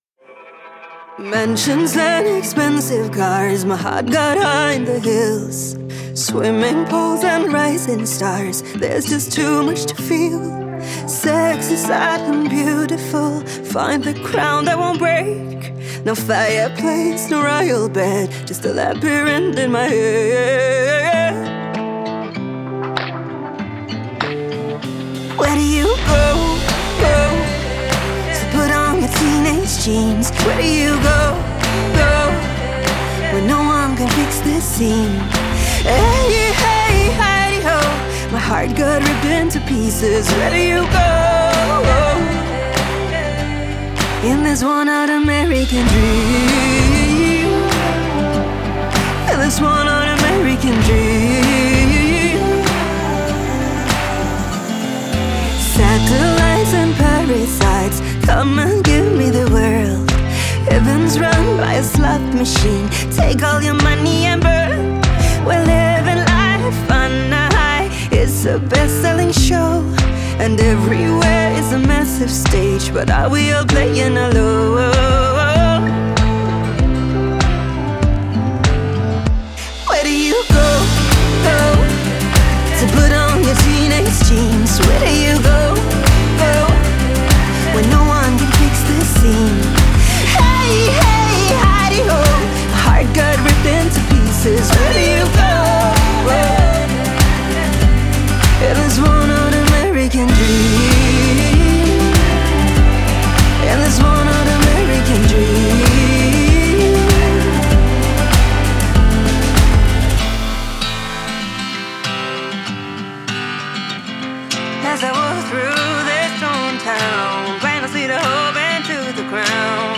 это поп-песня в стиле инди